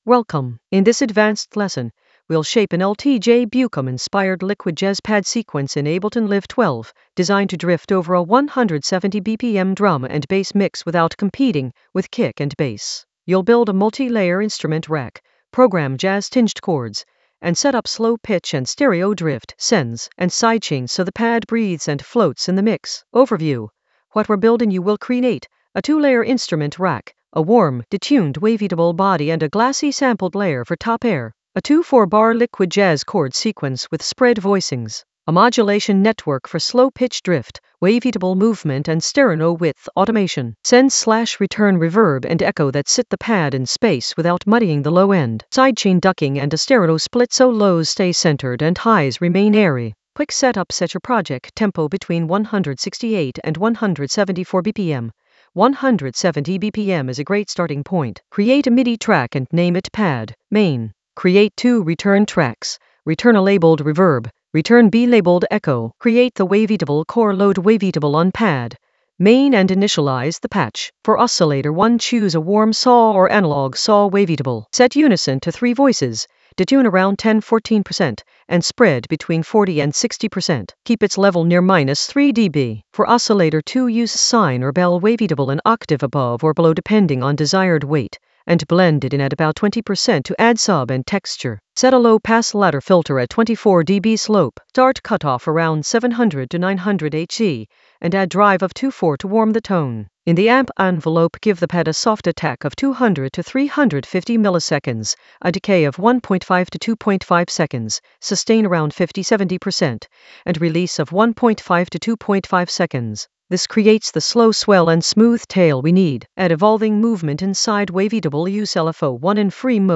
Narrated lesson audio
The voice track includes the tutorial plus extra teacher commentary.